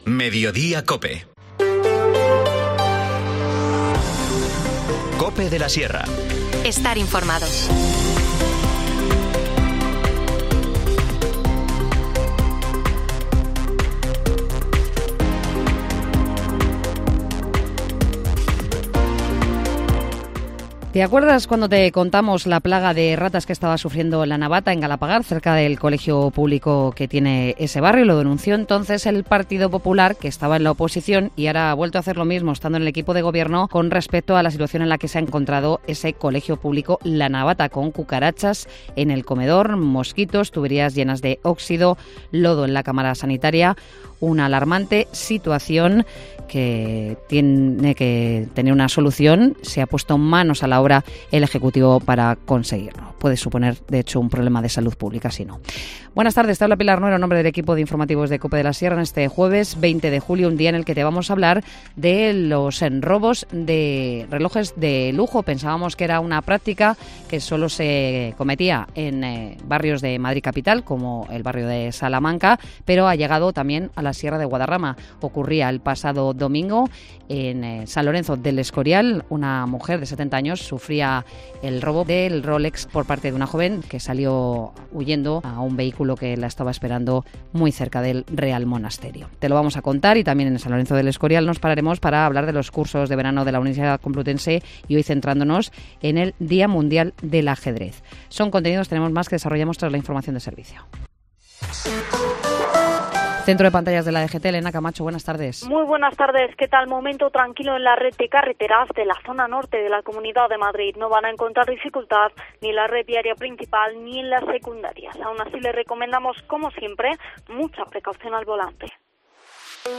Informativo Mediodía 20 julio